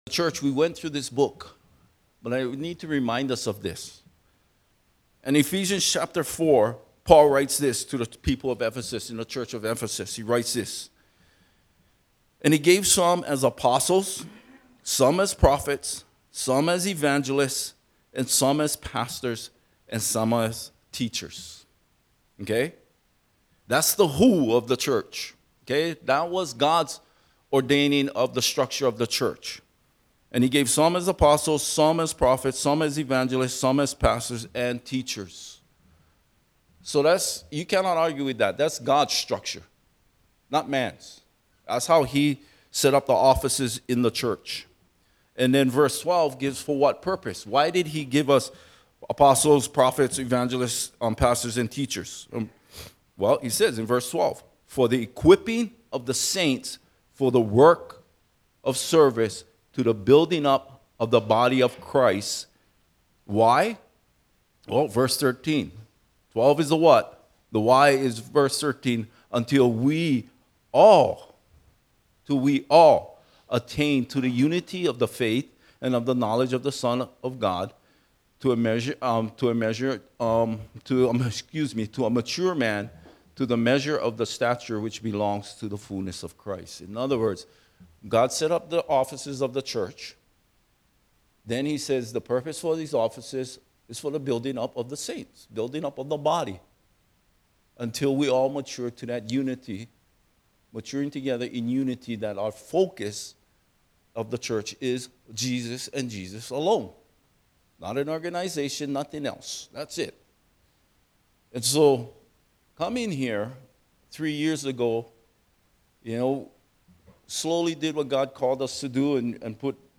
Topical Sermons - Imiola Church